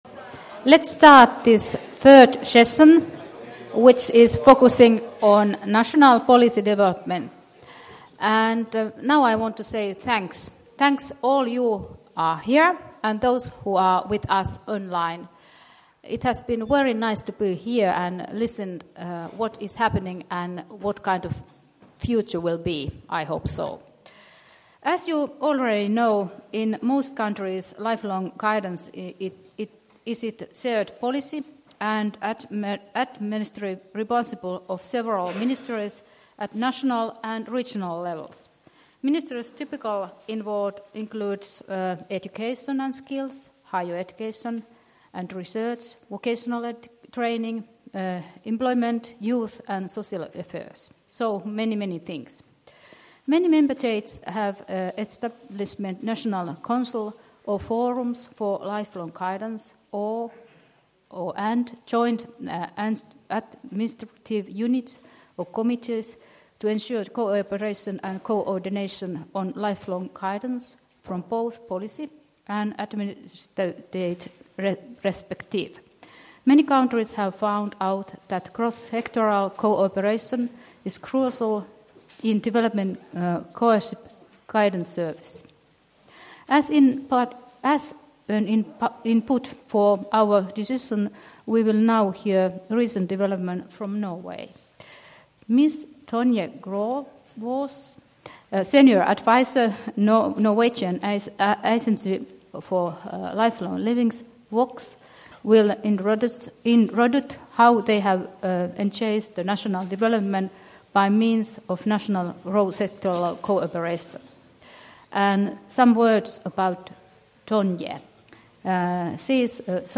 “Ohjaamo” One-Stop Guidance Centers: Developing Policy and Practice for Co-careering - National Lifelong Guidance Policy Seminar 26.11.2015 Jyväskylä.